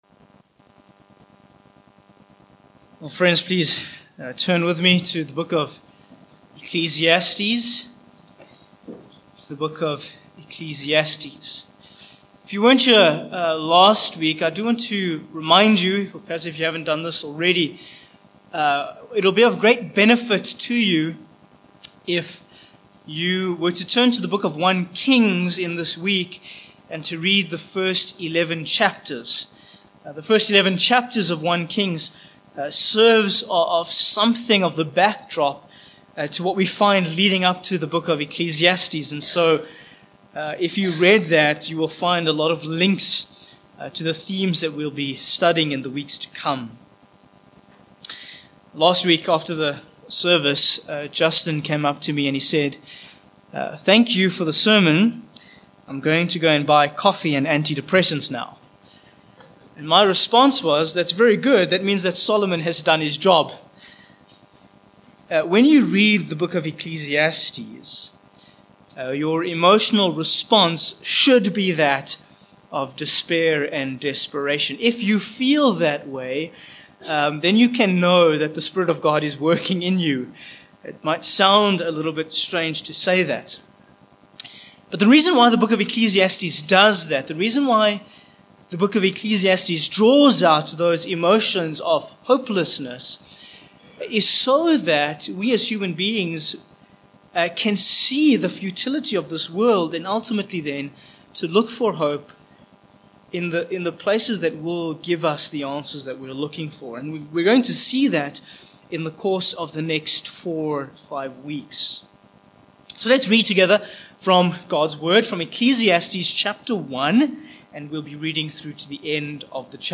Ecclesiastes 1:12-18 Service Type: Morning Passage